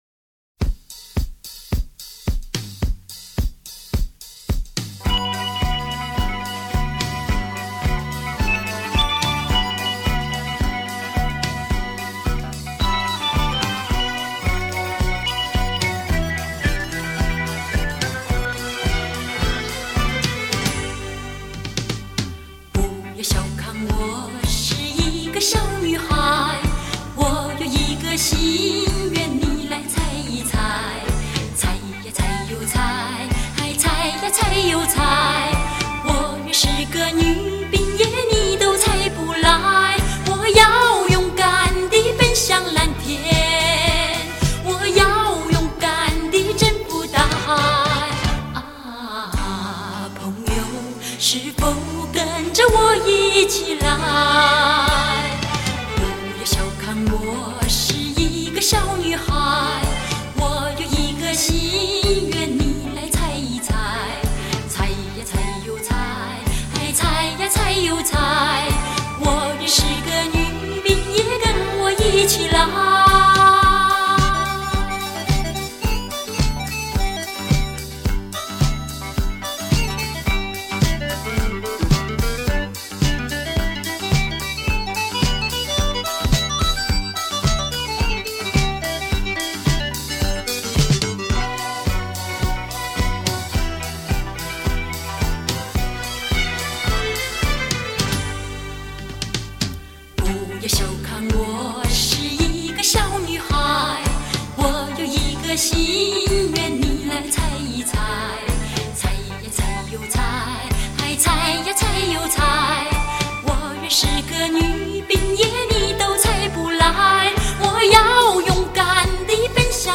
电影主题曲
毫无瑕疵的声音 唱着我们都曾经经历过的浪漫情愫